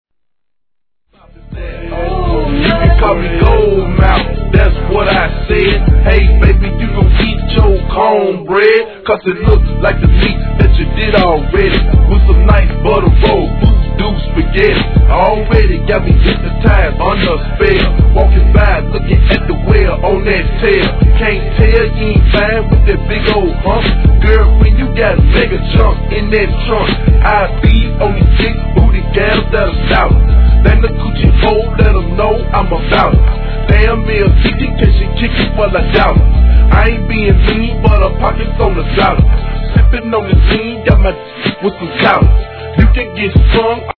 G-RAP/WEST COAST/SOUTH
粘りつくようなドラムパターンに淡々としたシンセがいかにも南部独特の雰囲気をかもし出します。